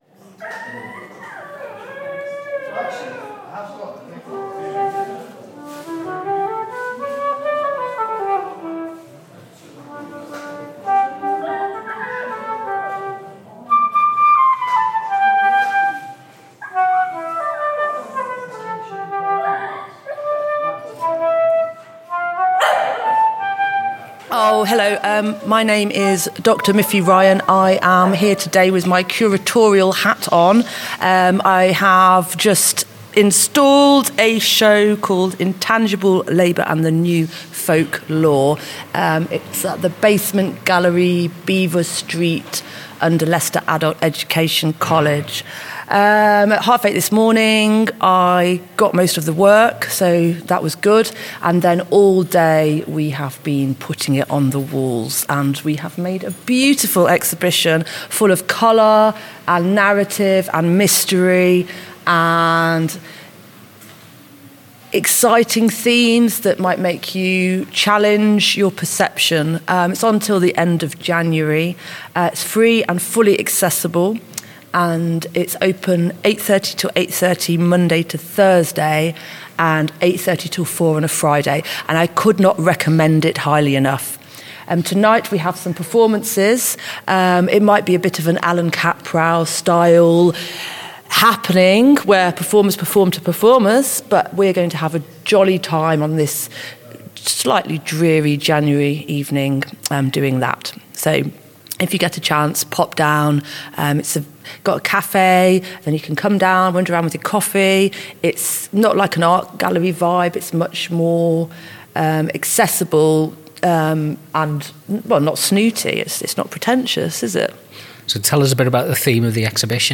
The exhibition and the accompanying podcast explore how much artistic work happens below the surface. Artists speak about the time, risk, and personal investment involved in making images, performances, and music.